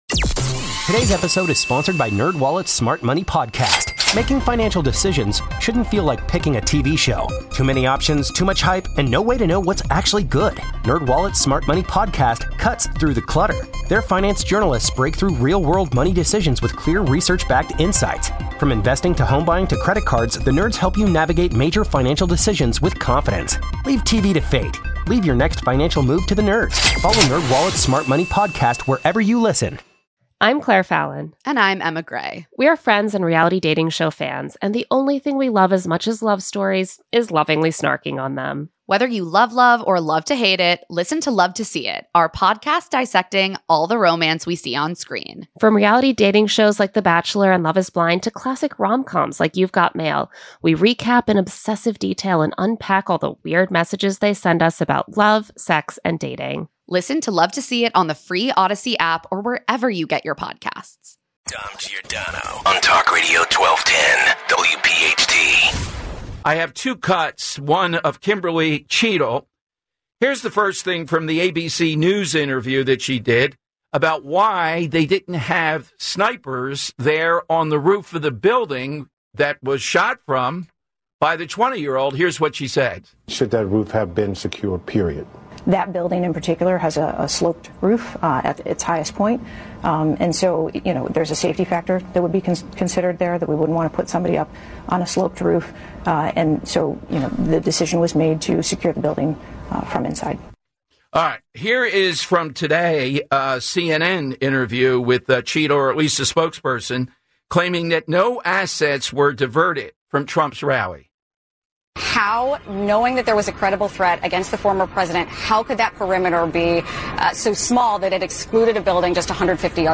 plays back clips from United States Secret Service Head Kimberly Cheatle